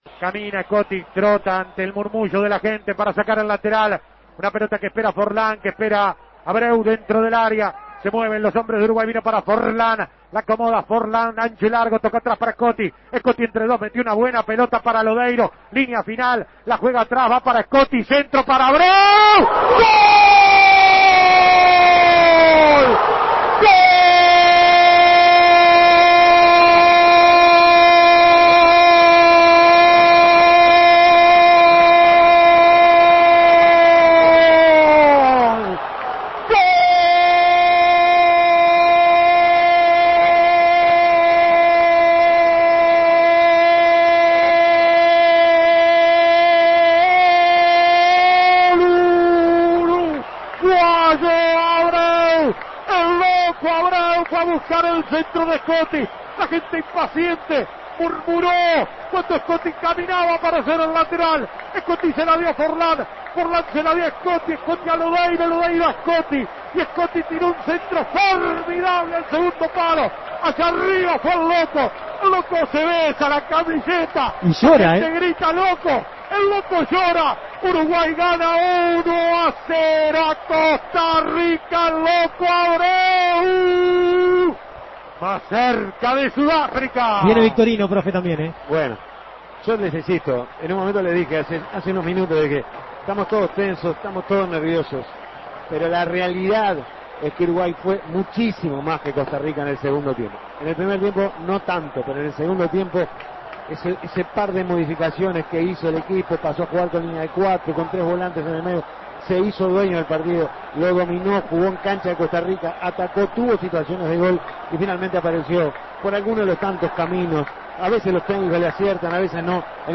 Goles y comentarios ¡Uruguay al Mundial!